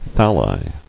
[thal·li]